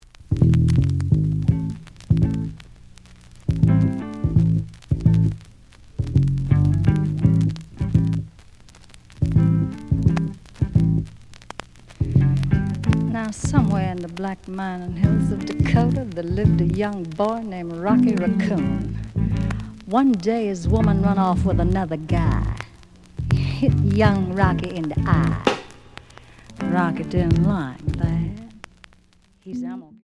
The audio sample is recorded from the actual item.
●Genre: Jazz Funk / Soul Jazz
●Record Grading: VG~VG+ (B面ノイジー。Some noise on B side.)